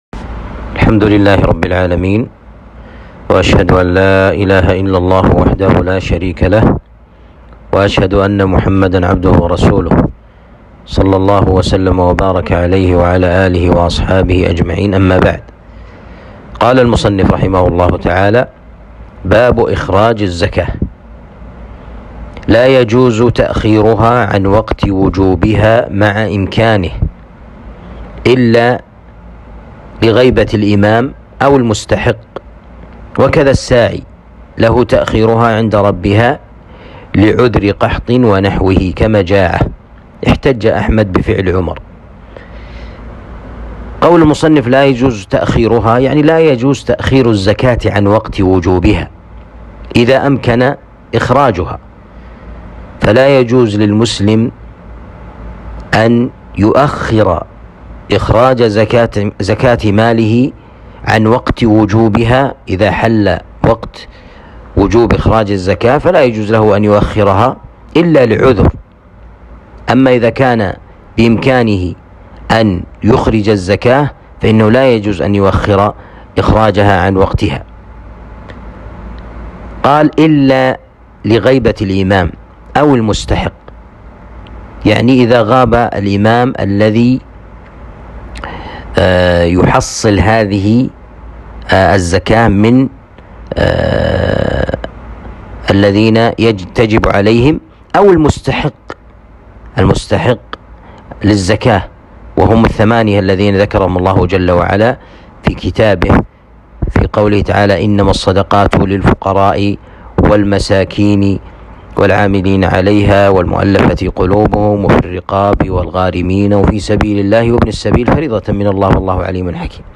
الدرس السابع والثلاثون